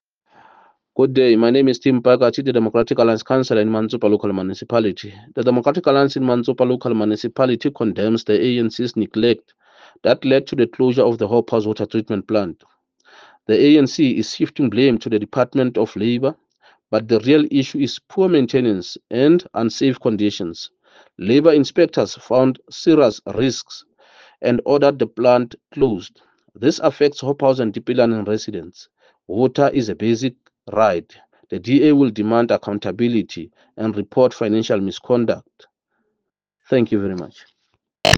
Sesotho soundbites by Cllr Tim Mpakathe and